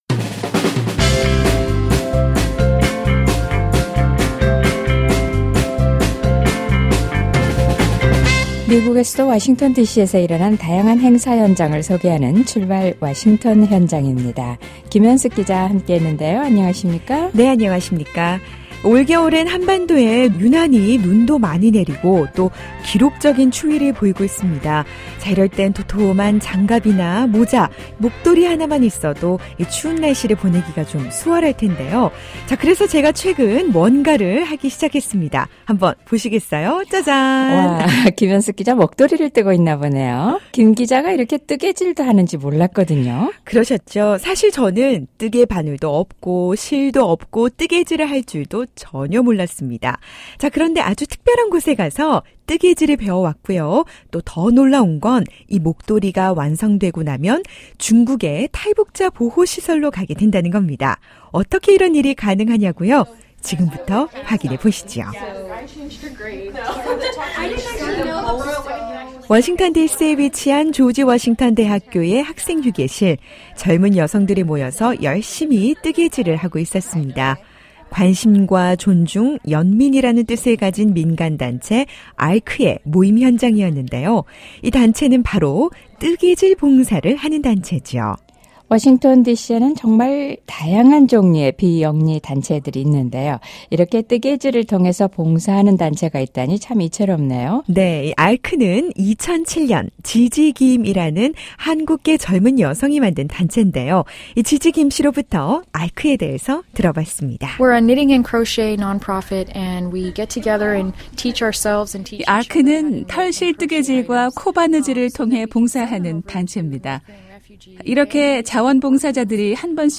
바로 뜨개질을 통한 봉사인데요, 자원봉사자들이 손수 짠 장갑과 모자, 목도리, 담요 등을 중국내 탈북자 보호소로 전달해 이 추운 겨울을 따뜻하게 보낼 수 있도록 돕고 있습니다. 털실만큼이나 포근하고 따뜻한 사랑이 넘쳤던 ARC의 뜨개질 모임현장을 만나봅니다.